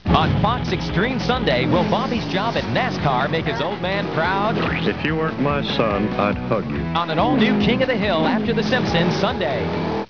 koh22110.mov (818k, Quicktime)   Audio Promo